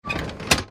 На этой странице представлена коллекция звуков рубильника — от четких металлических щелчков до глухих переключений.
Включили свет, рубильник переведен